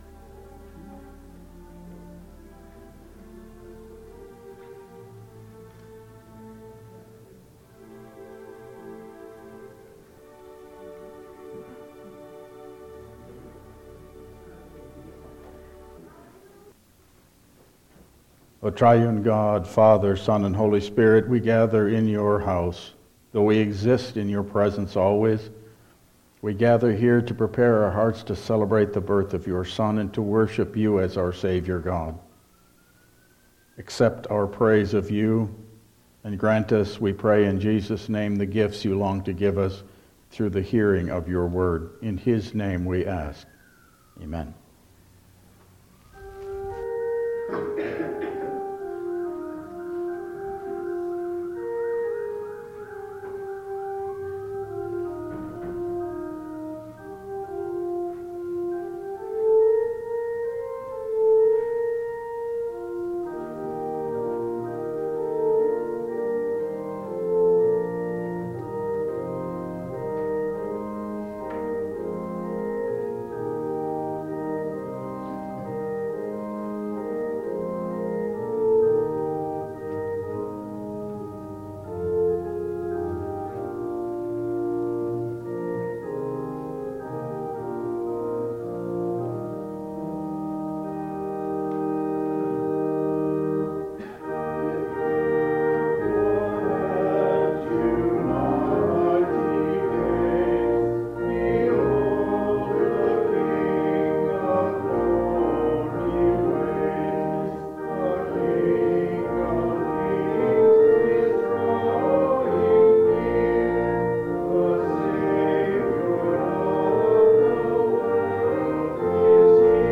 Service Type: Midweek Advent Service